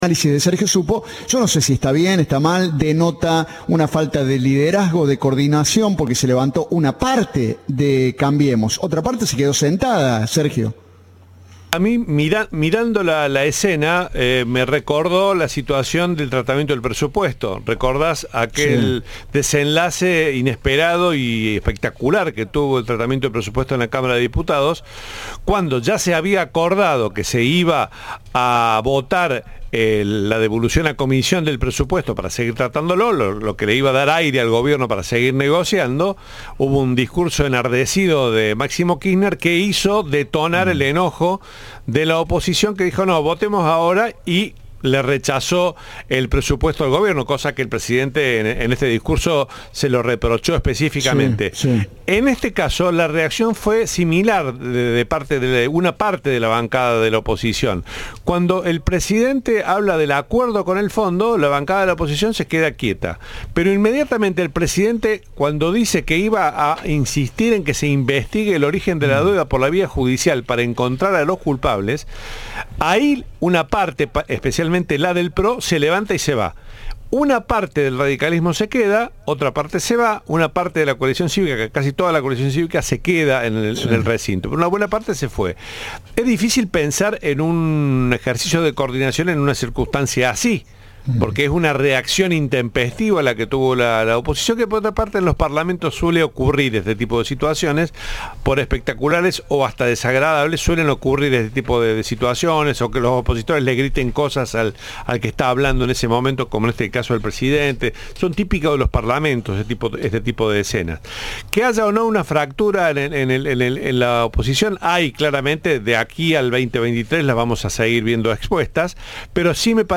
La alocución de Alberto Fernández se vio interrumpida por gritos y abucheos de la oposición, en el momento en que el jefe de Estado hablaba del acuerdo del ex presidente Mauricio Macri con el FMI.